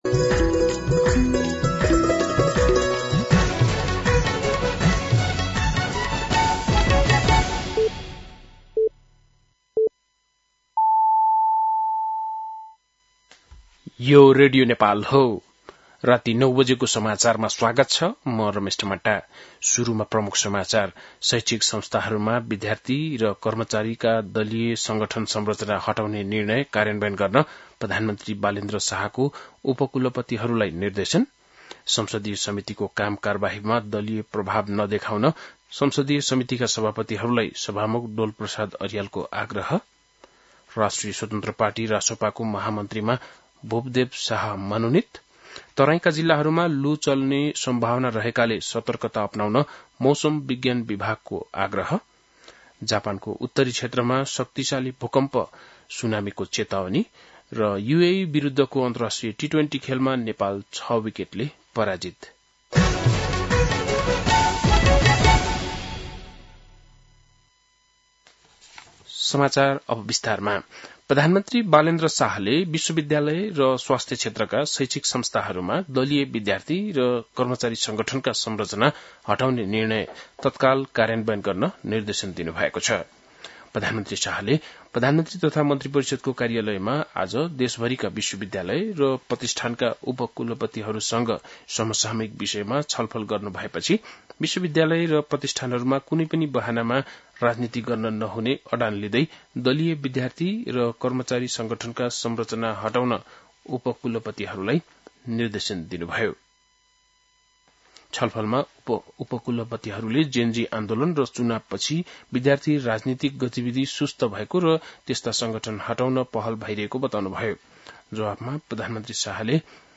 बेलुकी ९ बजेको नेपाली समाचार : ७ वैशाख , २०८३